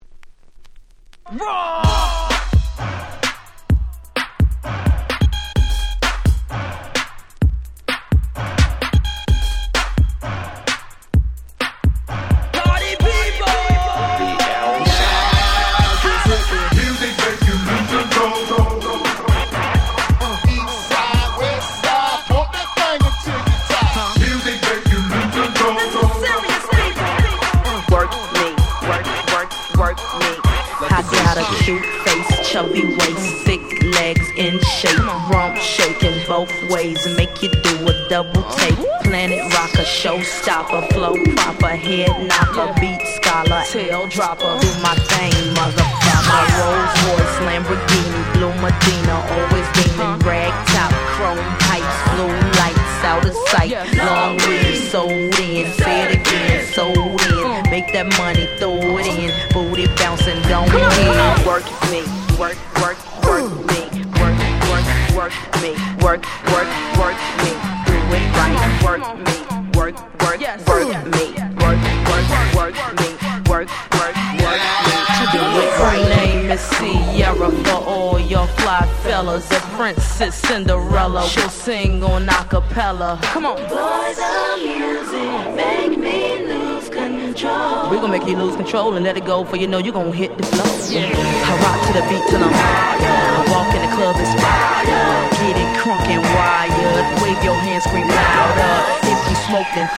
07' Nice Party Tracks / Mash Up !!
00's Hip Hop R&B